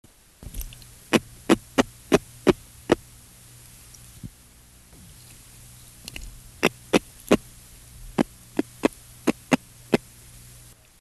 Sounds Made by Caranx hippos
Sound produced yes, active sound production
Type of sound produced grunts
Sound production organ teeth & swim bladder
Sound mechanism stridulation of well-toothed mouth reinforced by large swim bladder